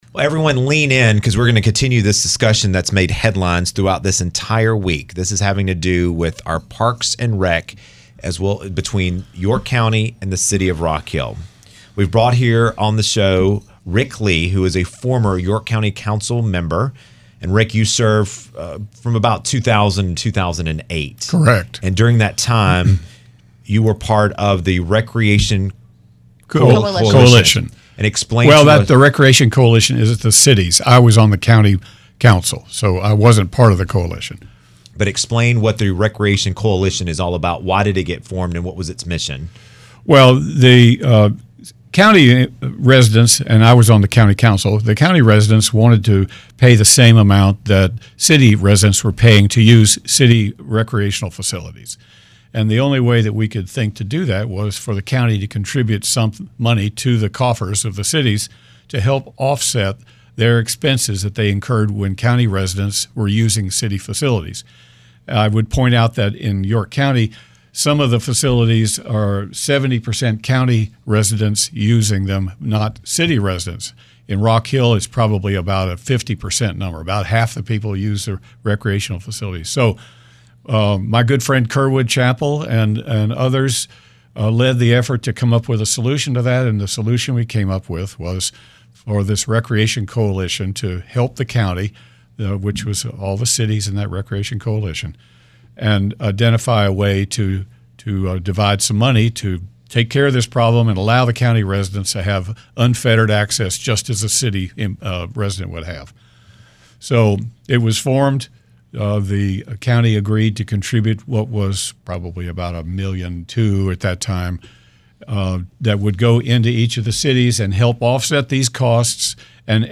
Discussion on York County and the County’s Cities on the future of Parks and Recreation